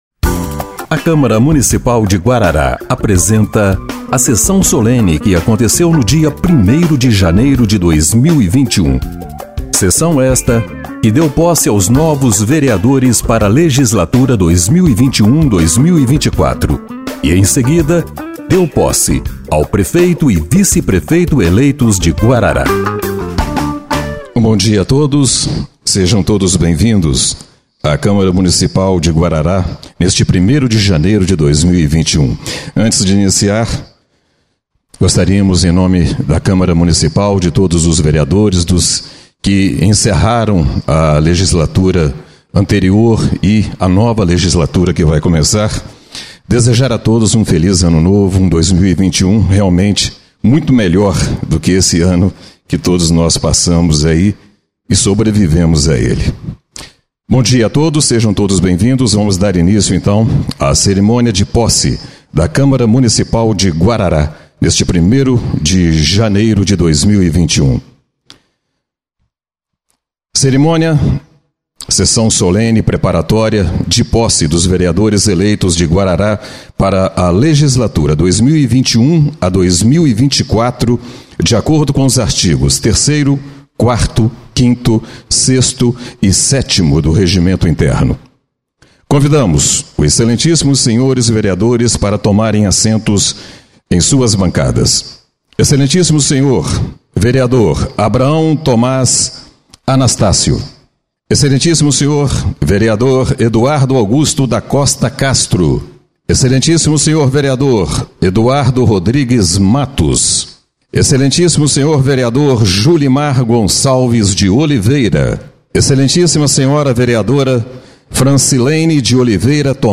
Sessão Solene de Posse de 01/01/2021